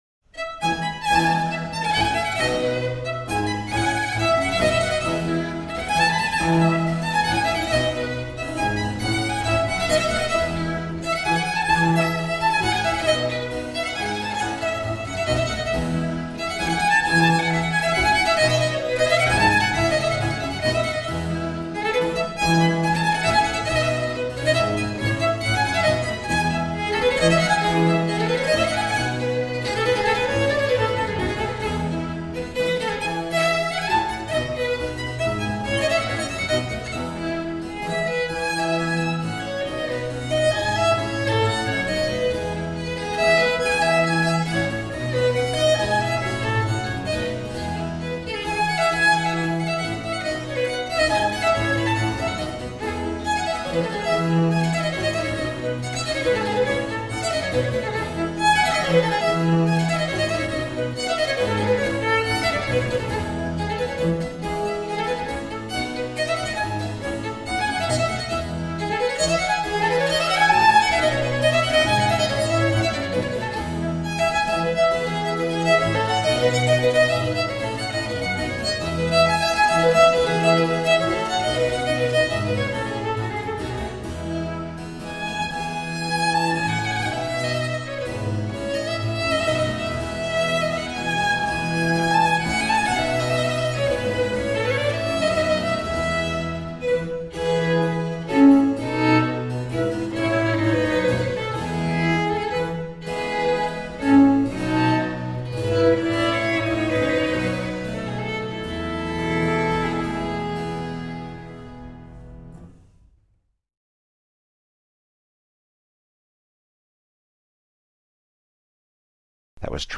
Small baroque chamber ensemble.
violinist
Baroque cellist
harpsichordist
Classical, Chamber Music, Baroque, Instrumental, Cello
Harpsichord